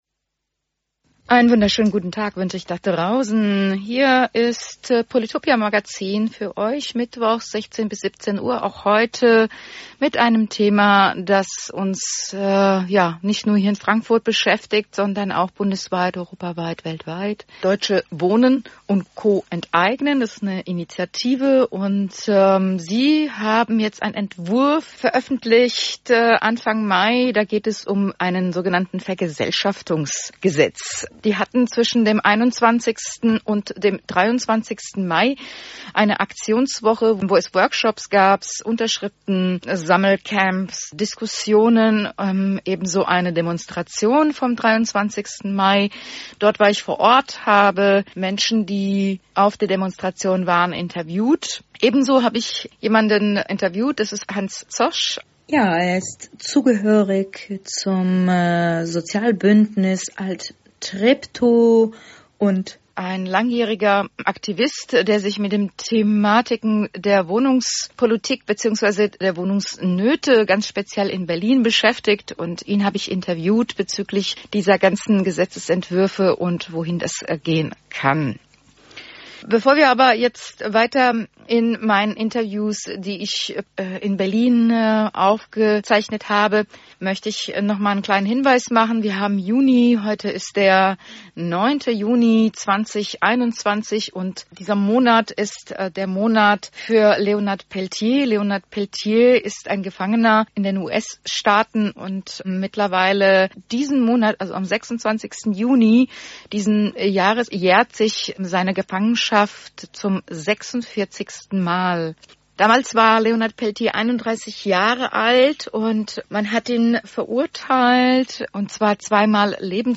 O-Töne von der Demonstration vom 23. Mai 2021 auf dem Potsdamer Platz.